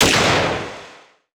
Weapons Demo
revolver_1.wav